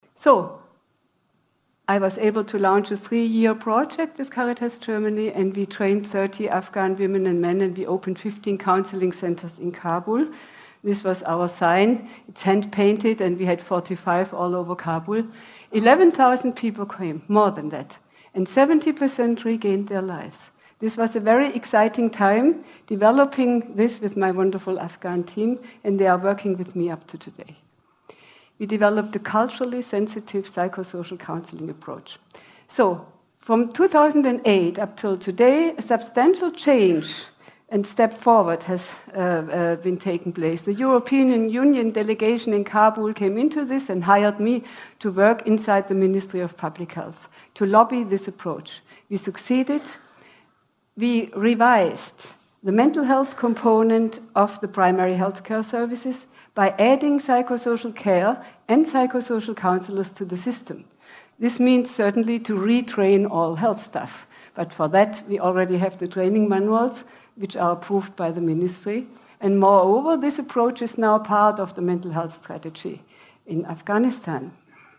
TED演讲:给阿富汗人带来心灵宁静(5) 听力文件下载—在线英语听力室